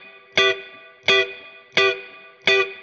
DD_StratChop_85-Emin.wav